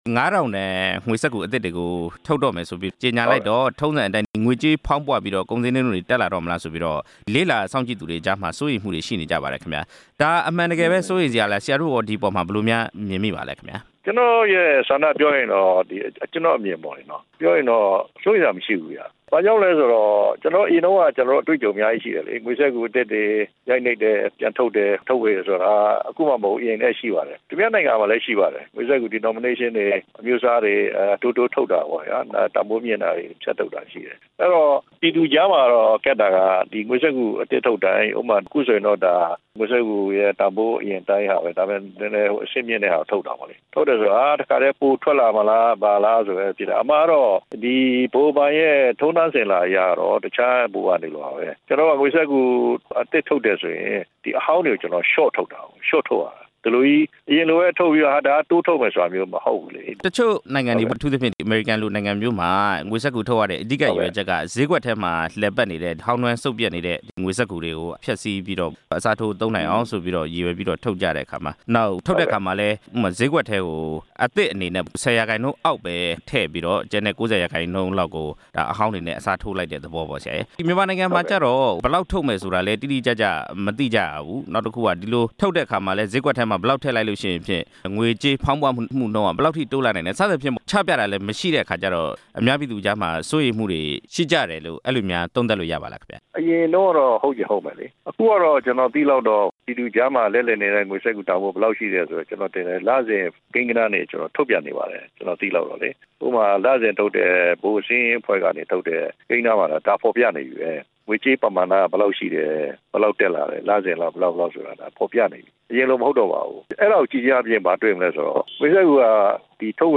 ကျပ် ငါးထောင်တန်သစ် ထုတ်ဝေမှု ဗဟိုဘဏ် ဒု-ဥက္ကဌဟောင်းနဲ့ မေးမြန်းချက်